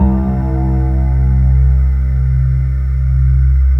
Index of /90_sSampleCDs/USB Soundscan vol.28 - Choir Acoustic & Synth [AKAI] 1CD/Partition C/13-MARJOLIE